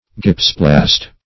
Search Result for " gypsoplast" : The Collaborative International Dictionary of English v.0.48: Gypsoplast \Gyp"so*plast\ (j[i^]p"s[-o]*pl[a^]st), n. [Gypsum + Gr. pla`ssein to mold.] A cast taken in plaster of Paris, or in white lime.